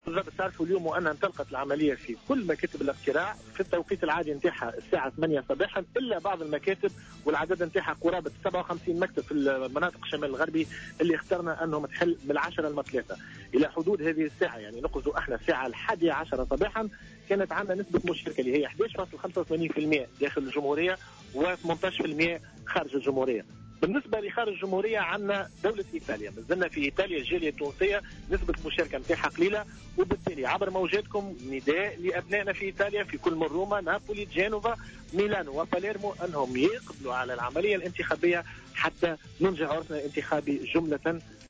A l'exception de 57 centres de vote du nord ouest du pays, l'ensemble des bureaux de vote du territoire ont ouvert, comme prévu, dimanche 23 novembre à 8 heures sans incidents notables jusqu’à présent, a déclaré au micro de Jawhara Fm Nabil Baffoun, membre de l'Instance Supérieure Indépendante pour les élections (ISIE).